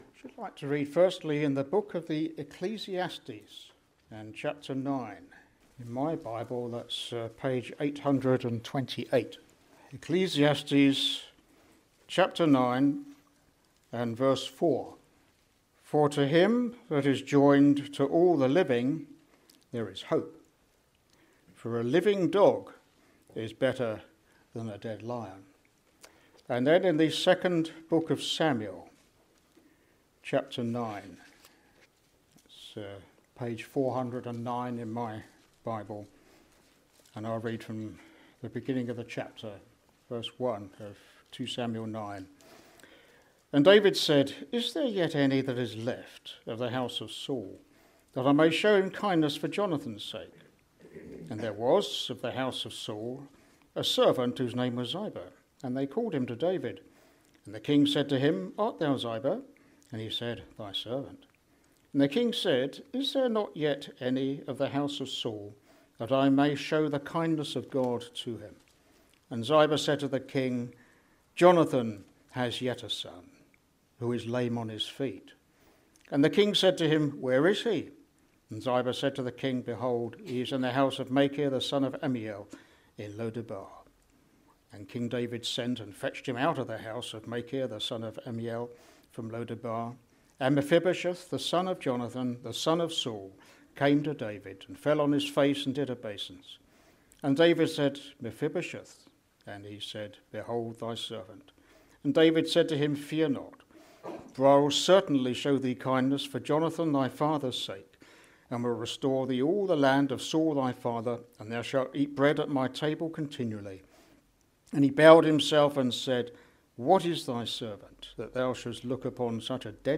The Gospel is preached to reveal the way of salvation to fallen mankind. This salvation is not found in a set of rules or ideas but is centred on a person: the Lord Jesus Christ.